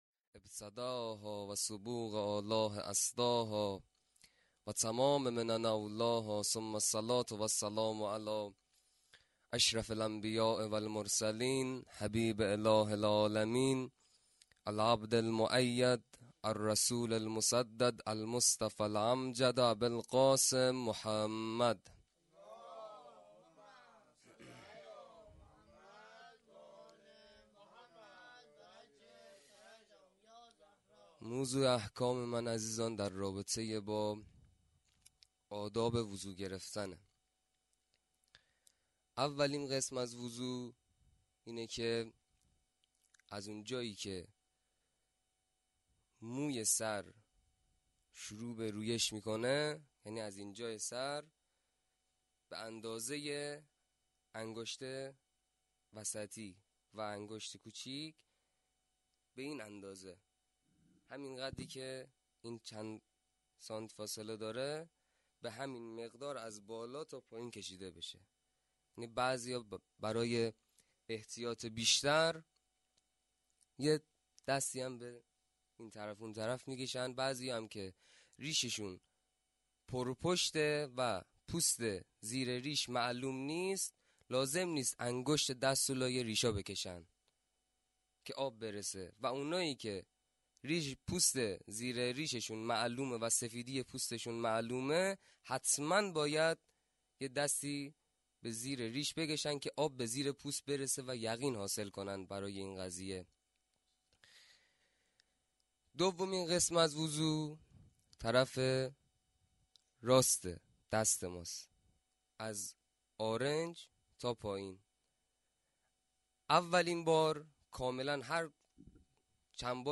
جشن ولادت حضرت زینب(س)- جمعه 29 دیماه